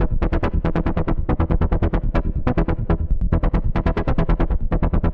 Index of /musicradar/dystopian-drone-samples/Droney Arps/140bpm
DD_DroneyArp1_140-E.wav